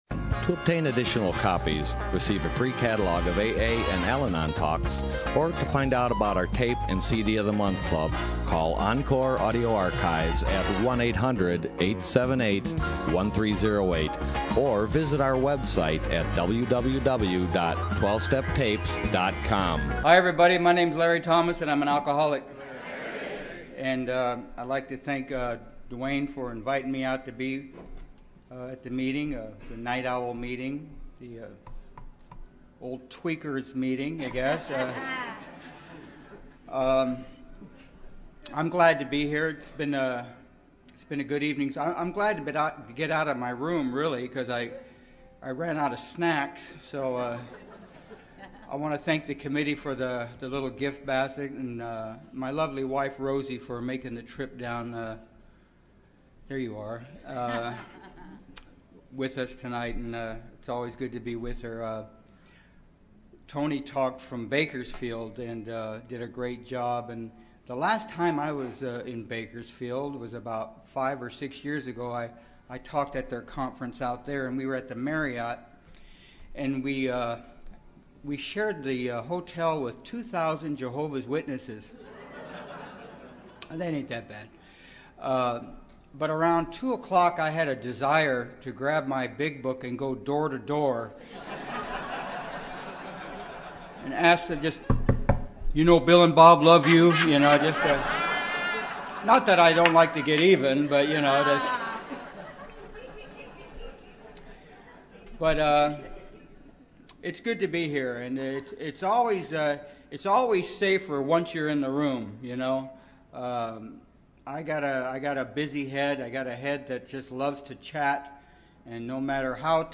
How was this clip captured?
Orange County AA Convention 2012